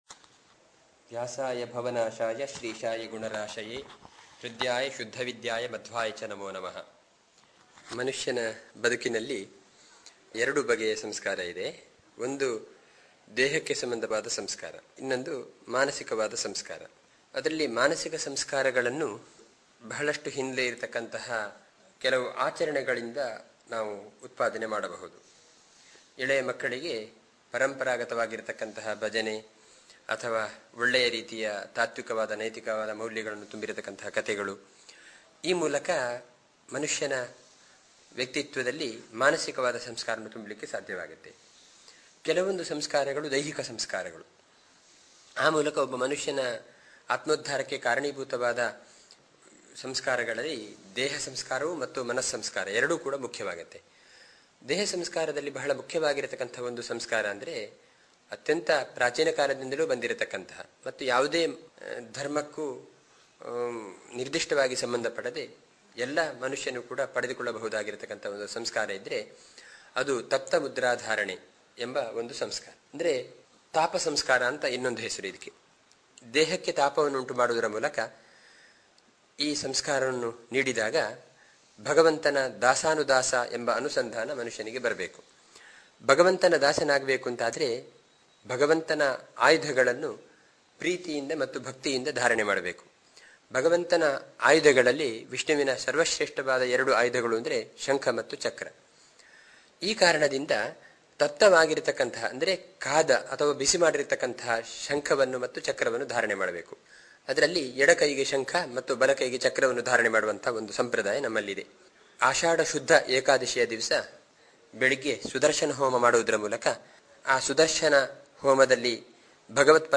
“ತಪ್ತಮುದ್ರಾಧಾರಣೆ”ಯ ಪ್ರಯುಕ್ತ ಶ್ರೀ ಶ್ರೀ ಶ್ರೀ ವಿದ್ಯಾ ಪ್ರಸನ್ನತೀರ್ಥ ಶ್ರೀಪಾದಂಗಳವರು, ಶ್ರೀ ಸಂಪುಟ ನರಸಿಂಹ ಮಠ, ಸುಬ್ರಹ್ಮಣ್ಯ ಇವರು ಇದರ ಮಹತ್ವ ಹಾಗೂ ವಿಚಾರಗಳೊಂದಿಗೆ ಅಭಿಮಾನದಿಂದ ನಮ್ಮ ಸಮಾಜವನ್ನು ಉದ್ದೇಶಿಸಿ ಸಂದೇಶವನ್ನು ನೀಡಿರುತ್ತಾರೆ.